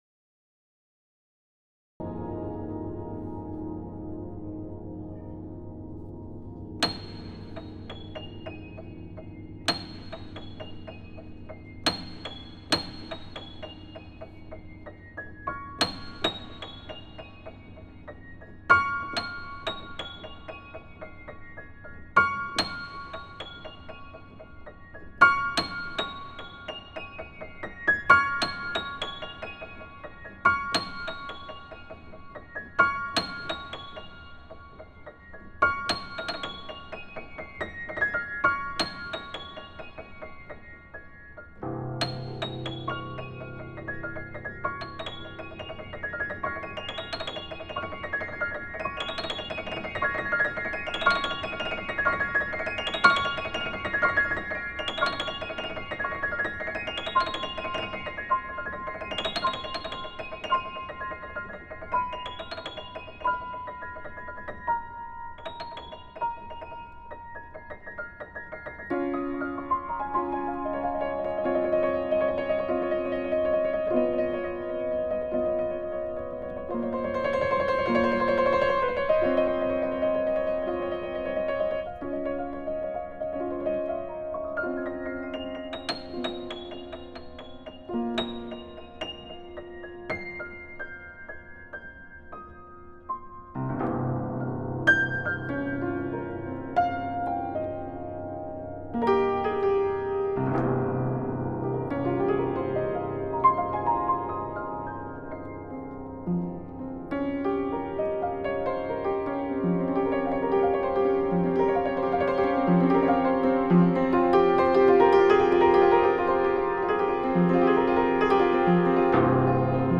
I’ve just published the 4th entry in Page 4 of the Sketchbook series: a solo piano recording called “Snowstorm”. I made this recording on a public Yamaha upright, in which I noticed a few particular qualities: in general, a very muted character; in the highest octave of the keyboard, a particularly sparkly, chilly ambiance (especially when played forte); and, in the lowest octave, a sort of cloudy, percussive, almost prepared-piano timbre. This new recording makes use of these extreme registers to create a variety of tempestuous effects.